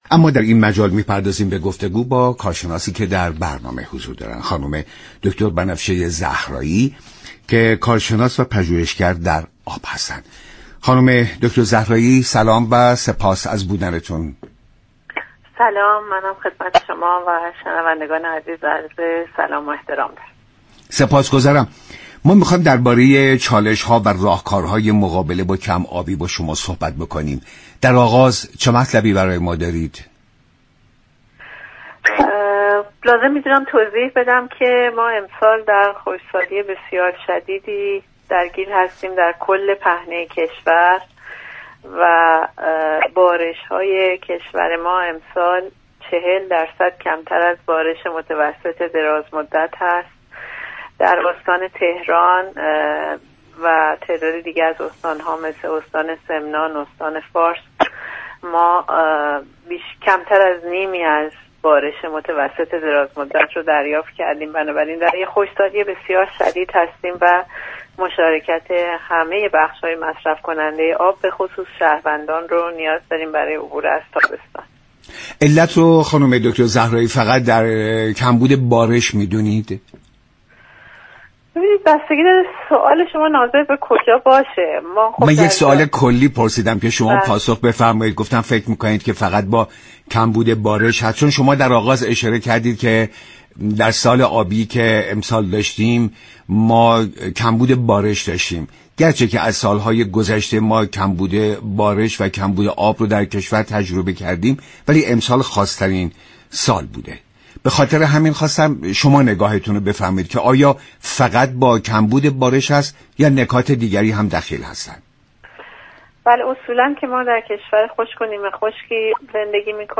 كارشناس و پژوهشگر حوزه آب در برنامه دستوركار گفت: علاوه بر كاهش میزان بارش‌ها در ایران، گرمایش جهانی و تغییر اقلیم نیز باعث كمبود آب در كشور شده است.